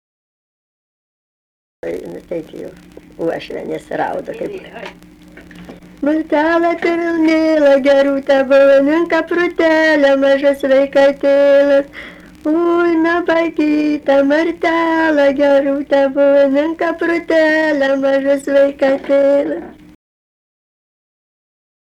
daina, vestuvių
Erdvinė aprėptis Mantvydai
Atlikimo pubūdis vokalinis